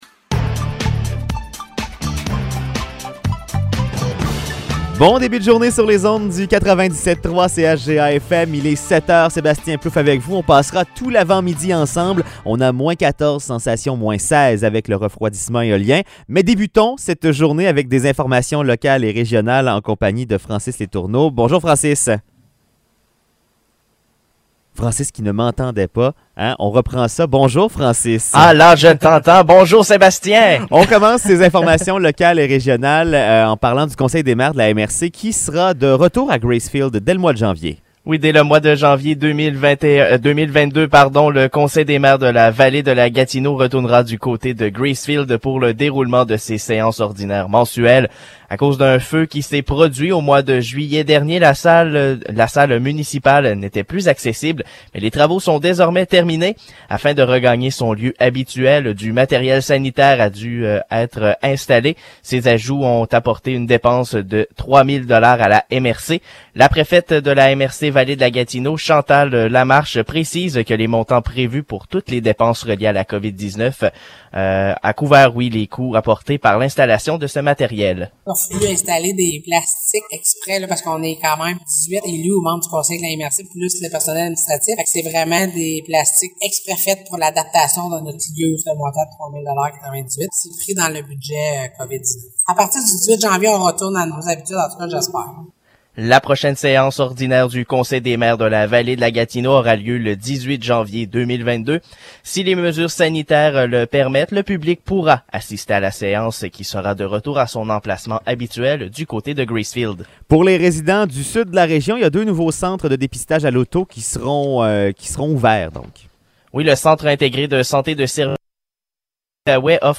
Nouvelles locales - 29 décembre 2021 - 7 h